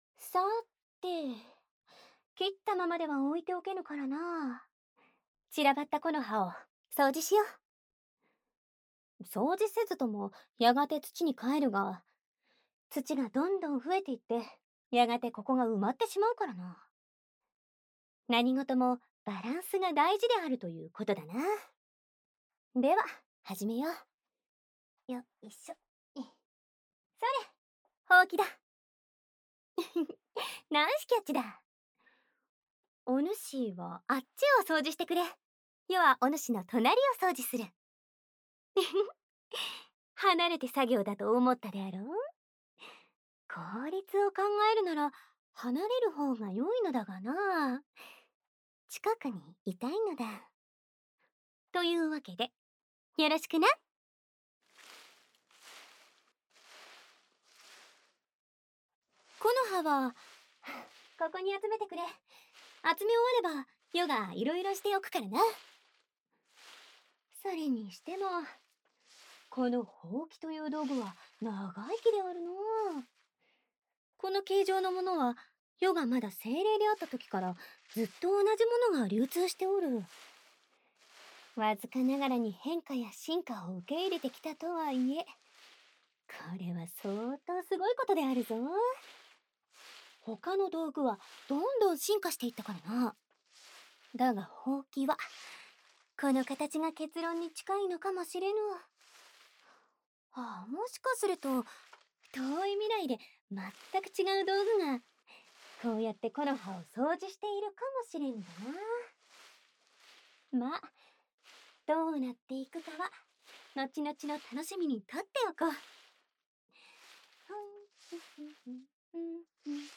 温馨 幻想 治愈 掏耳 环绕音 ASMR 低语